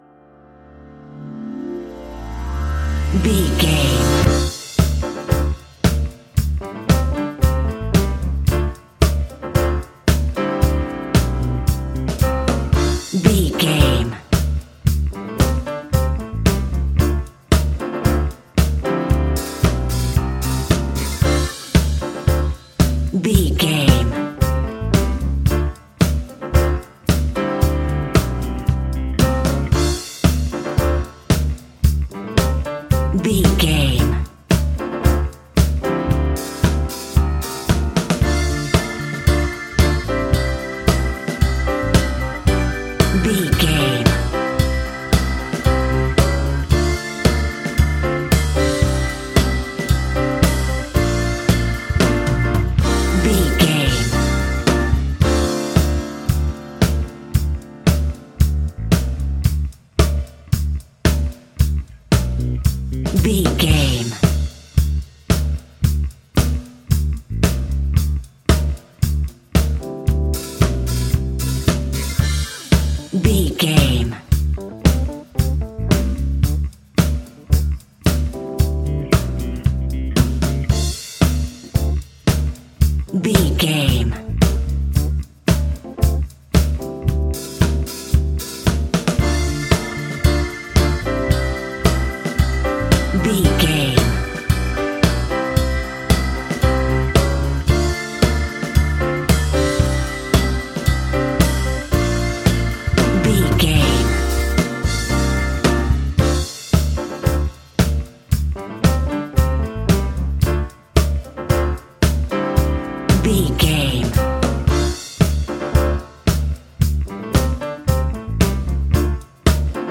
Ionian/Major
D
house
electro dance
synths
techno
trance
instrumentals